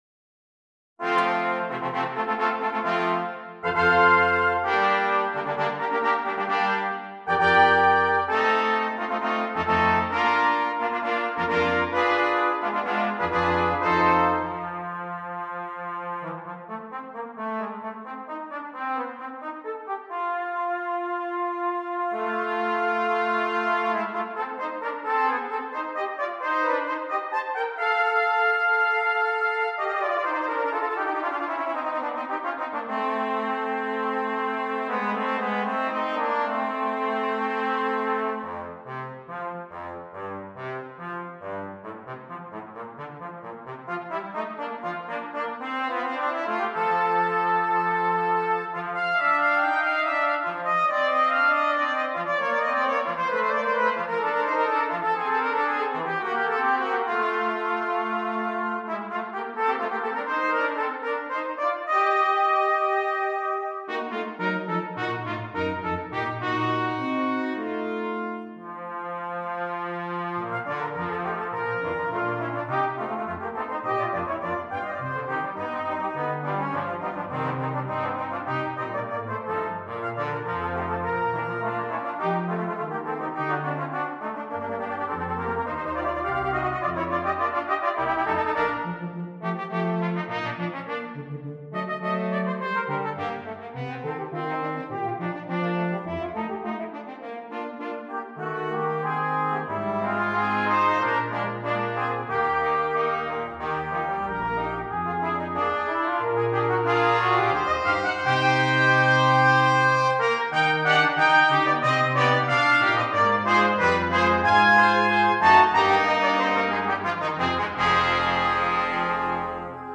Brass Quintet
brass quintet features the first trumpet and the trombone